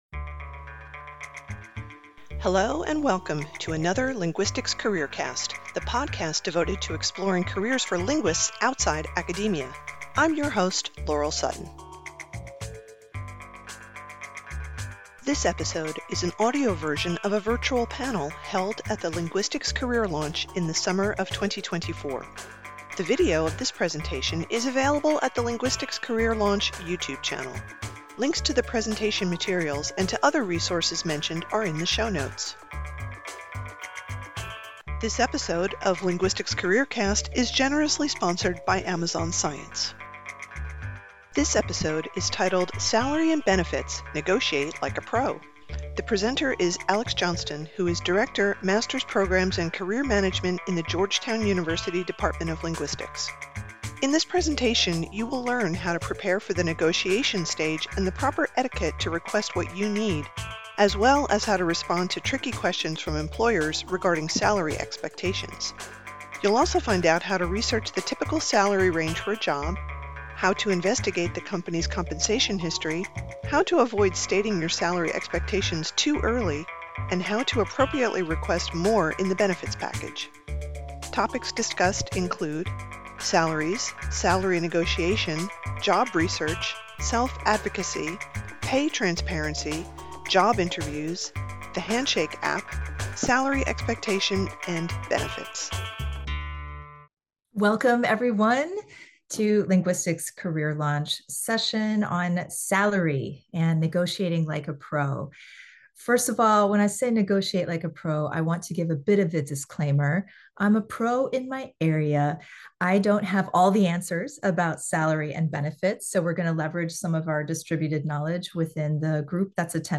In this presentation, you will learn how to prepare for the negotiation stage and the proper etiquette to request what you need, as well